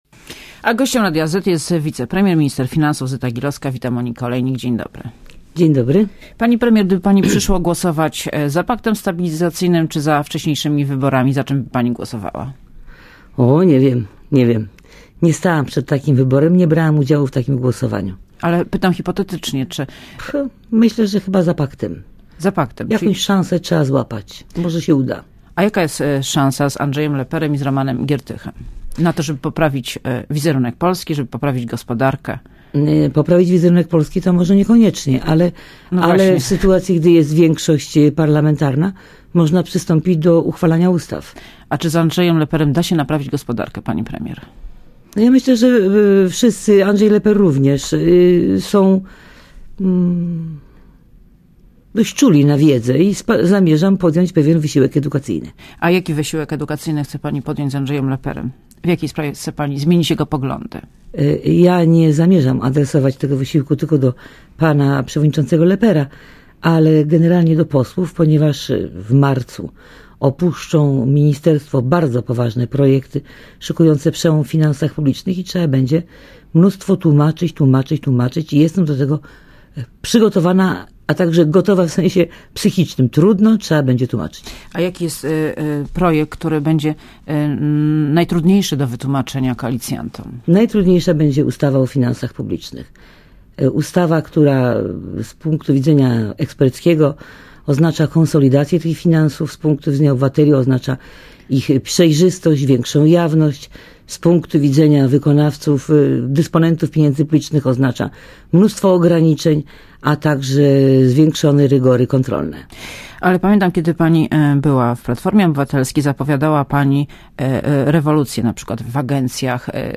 Gościem Radia ZET jest wicepremier i minister finansów Zyta Gilowska. Wita Monika Olejnik, dzień dobry.